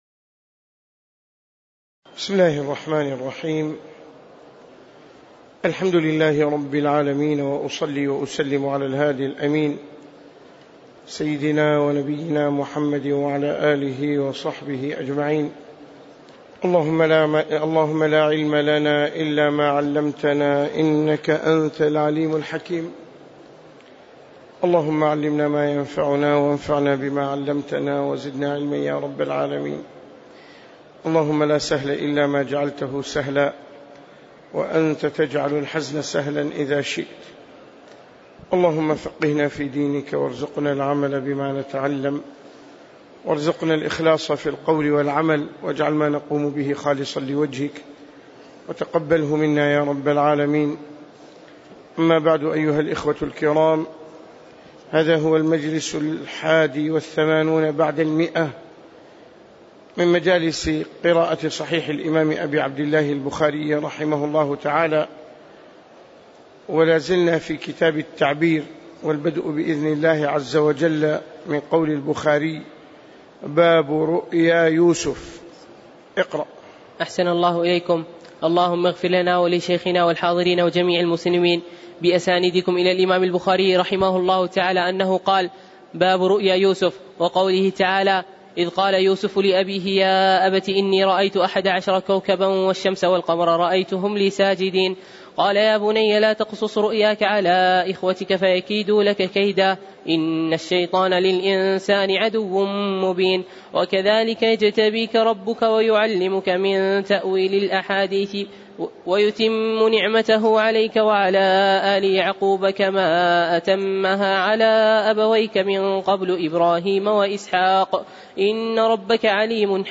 تاريخ النشر ١٢ ربيع الأول ١٤٣٩ هـ المكان: المسجد النبوي الشيخ